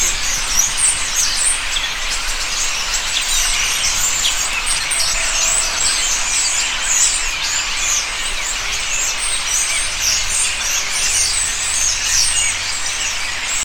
Había cientos y cientos de estorninos en los árboles, que con sus cantos producían un sonido ensordecedor y que no dejaba indiferente a la persona que los escuchaba. Esa hora era al atardecer.
Estorninos.mp3